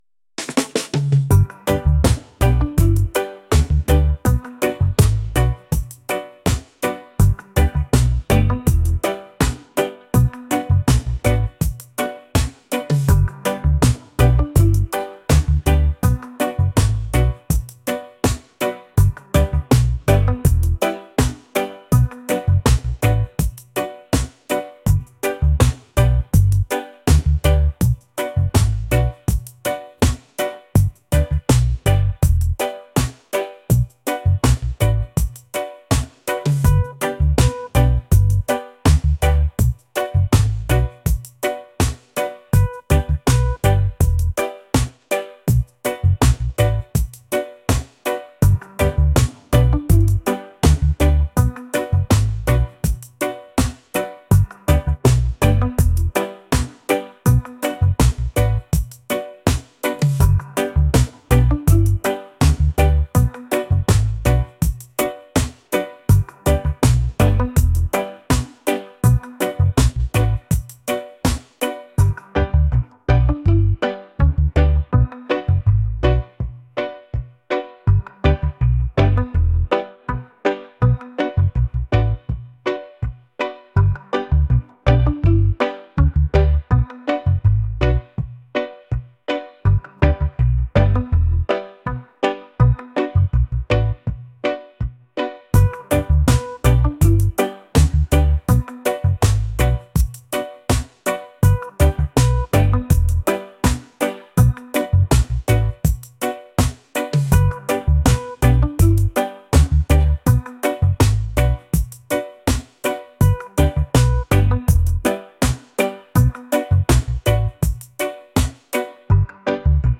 relaxed | reggae